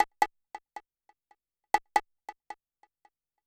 COW CONGA -L.wav